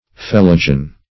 Search Result for " phellogen" : The Collaborative International Dictionary of English v.0.48: Phellogen \Phel"lo*gen\, n. [Gr. fello`s cork + -gen.]
phellogen.mp3